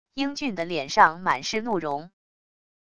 英俊的脸上满是怒容wav音频生成系统WAV Audio Player